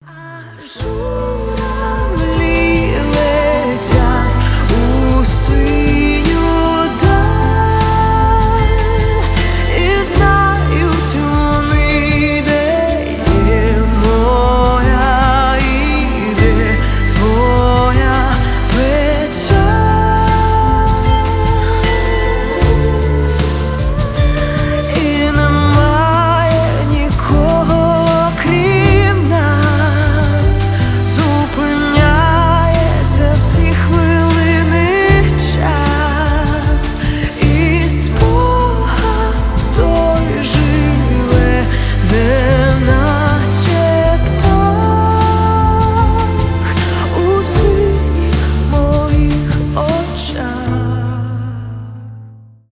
медленные
поп , романтические